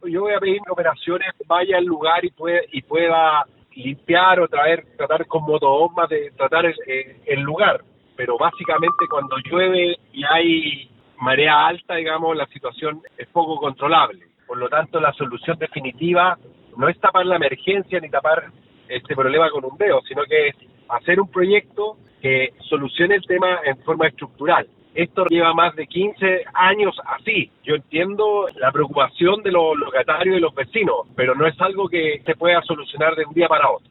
Al ser consultado por Radio Bío Bío, el funcionario comprometió la limpieza del pasillo de pescadería, pero también reconoció que se necesita una solución más estructural.